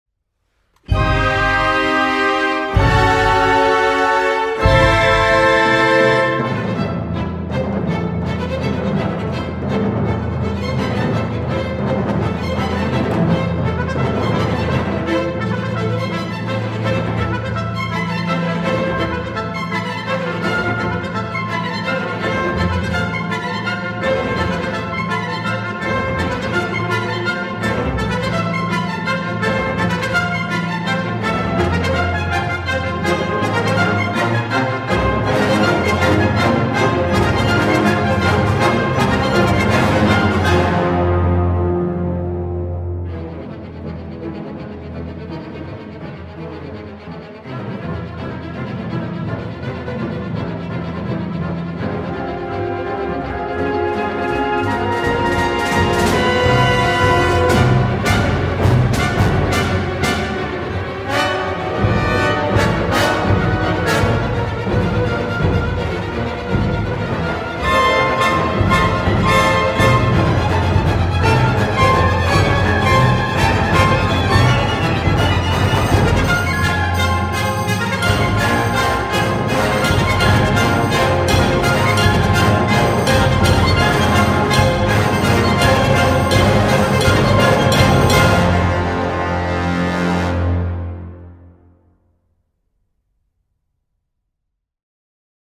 Une très bonne prestation de l’orchestre.